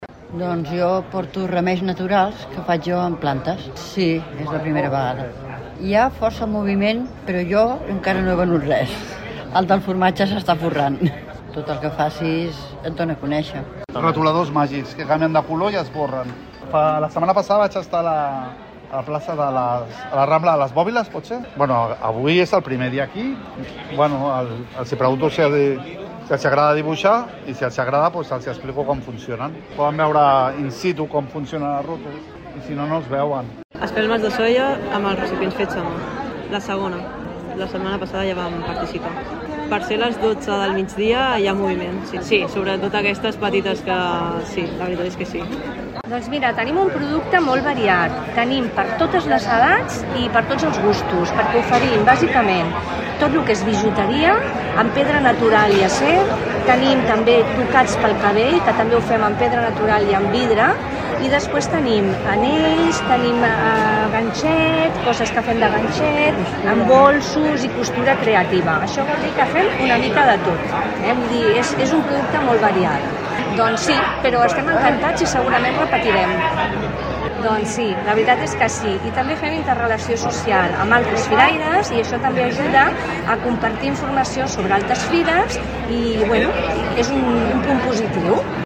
Paradistes Fira del Comerç i l'Artesania